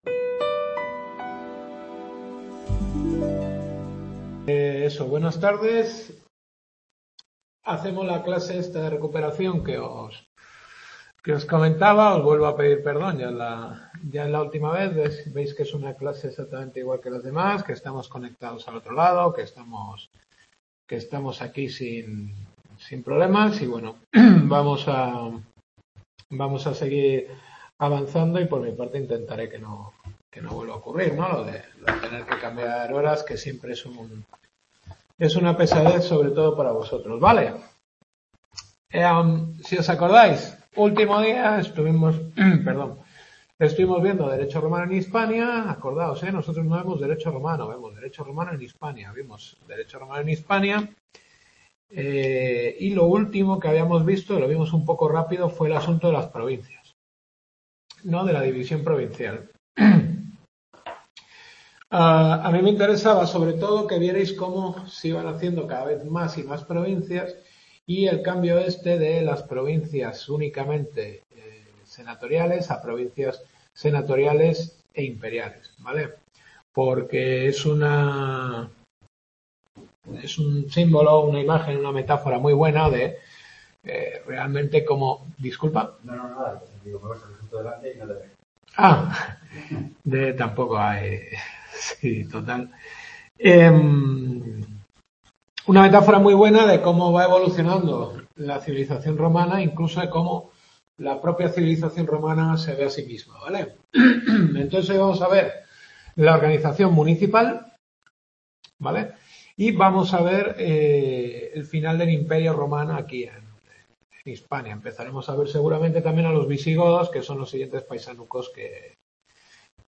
Tercera Clase.